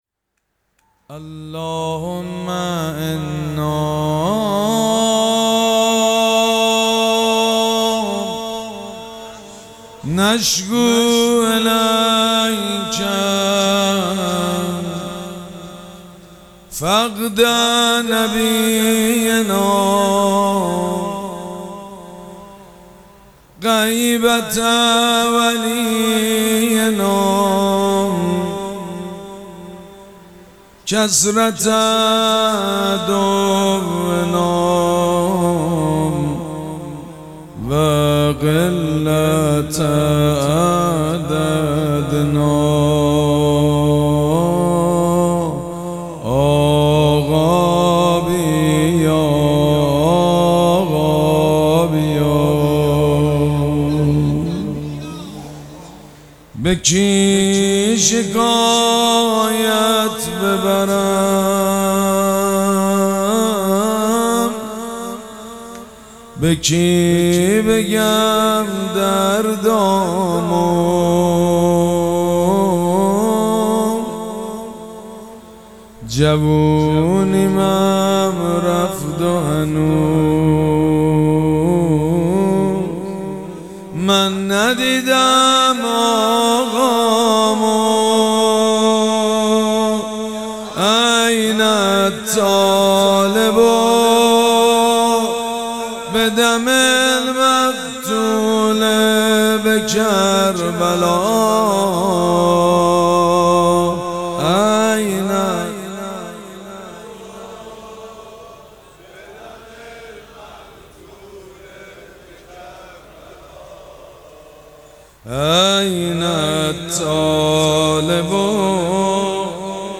شب پنجم مراسم عزاداری اربعین حسینی ۱۴۴۷
مناجات
حاج سید مجید بنی فاطمه